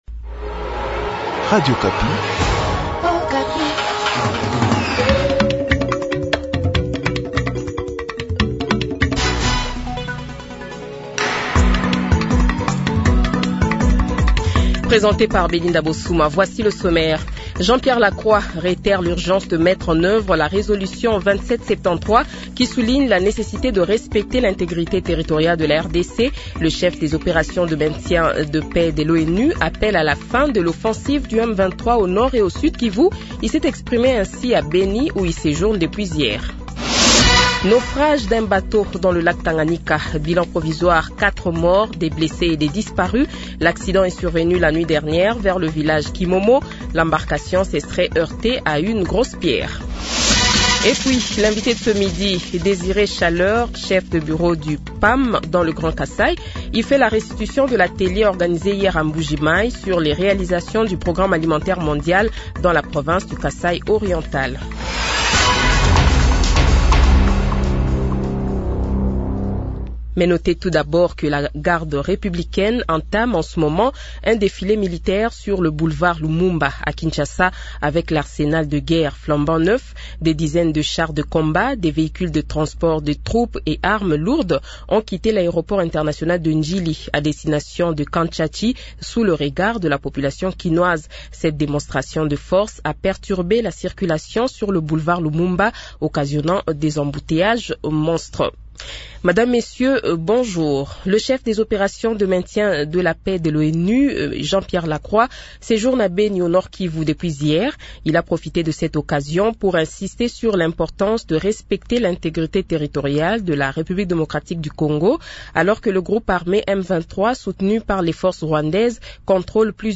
Le journal de 12h, 1er Mars 2025 :